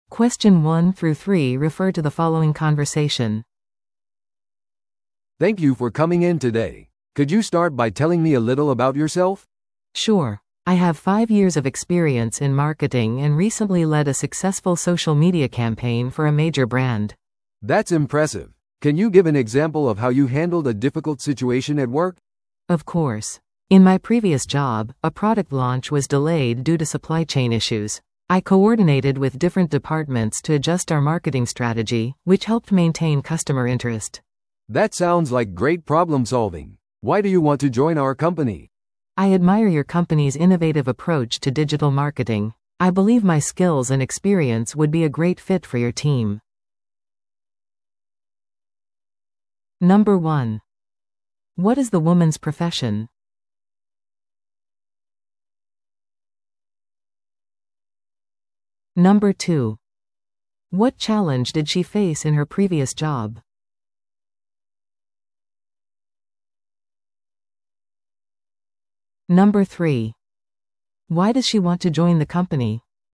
No.1. What is the woman’s profession?